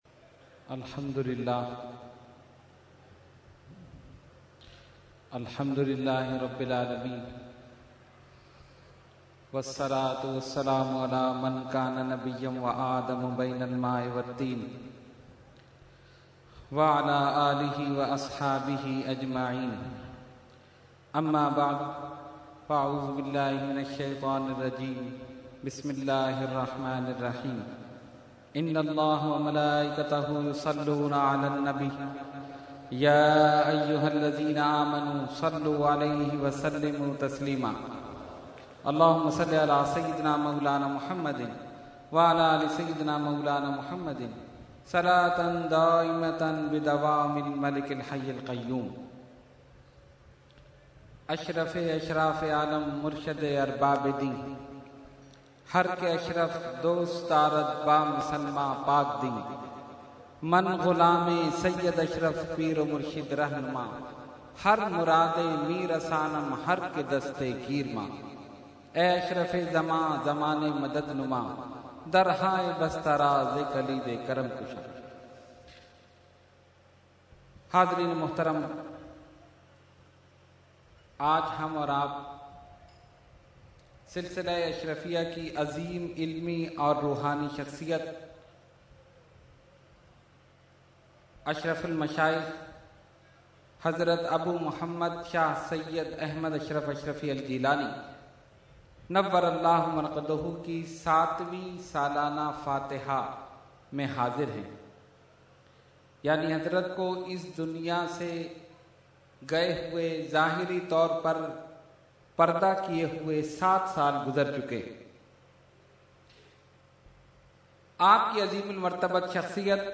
Category : Speech